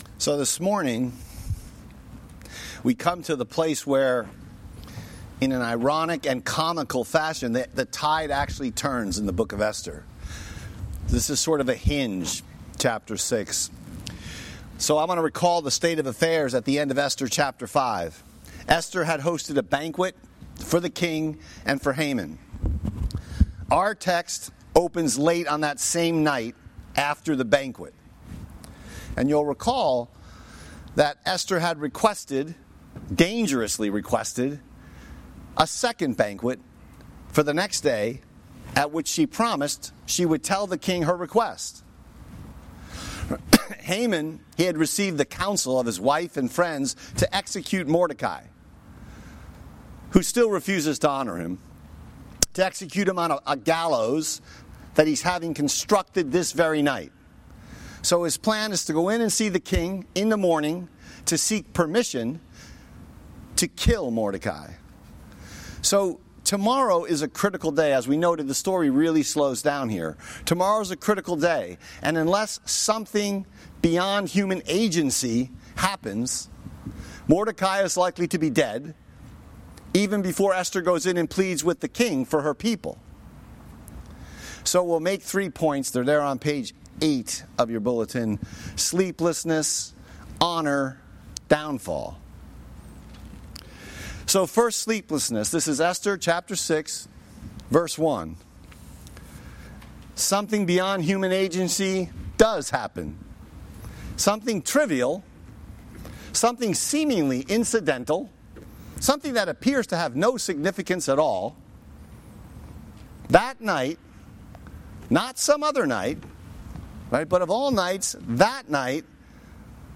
Sermon Text: Esther 6:1-14